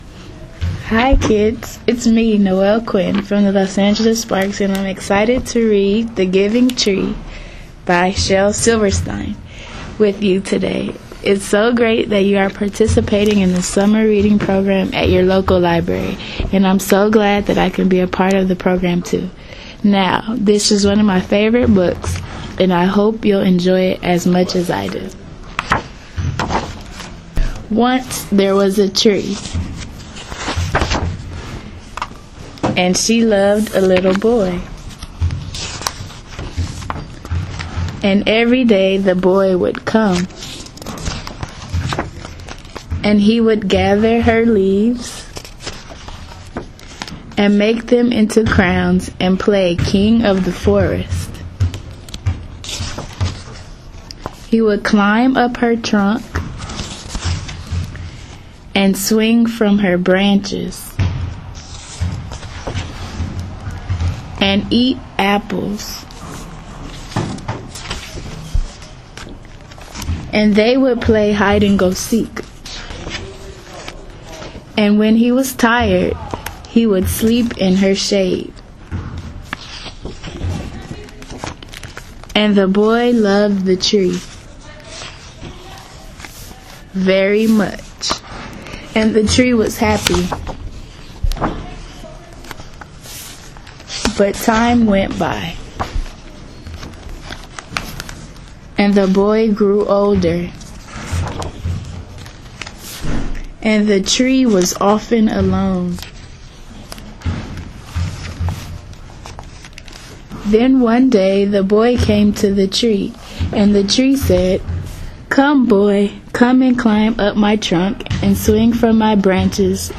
reads from \"The Giving Tree\" by Shel Silverstein.